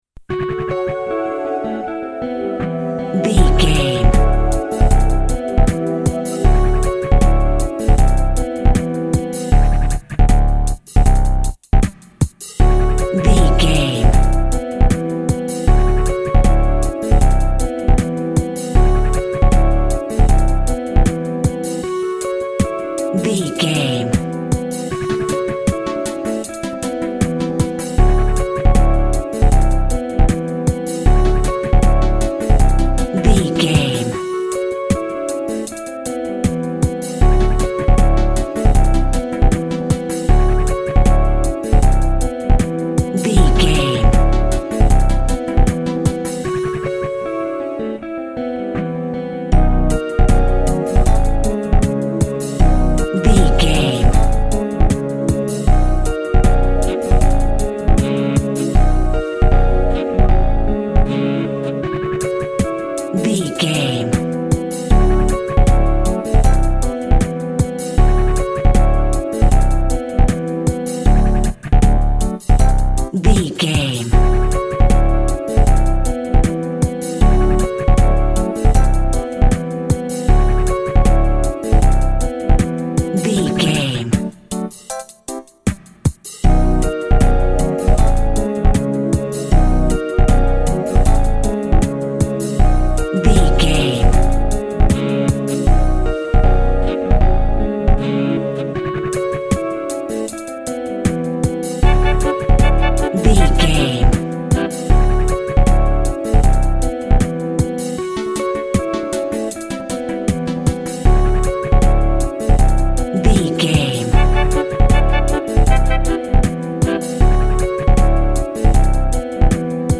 Ionian/Major
D
hip hop
synth lead
synth bass
hip hop synths
electronics